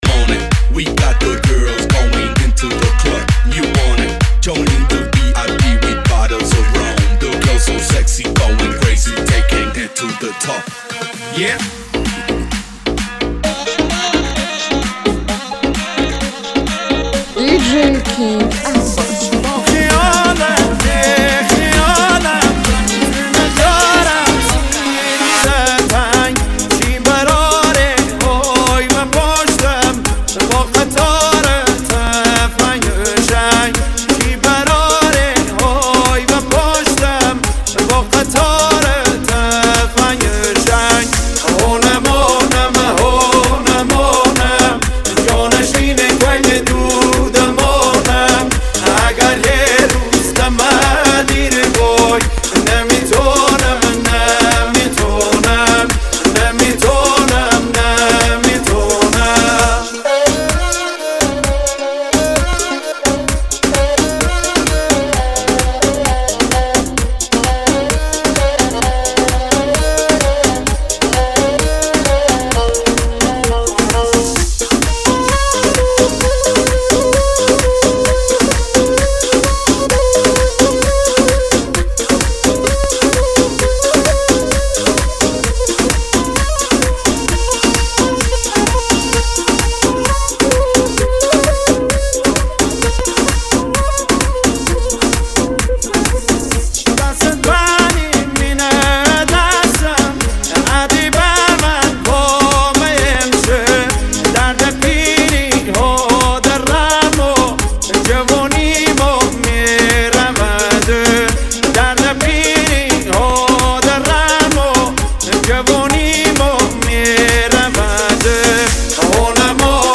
دانلود آهنگ لری خرم آبادی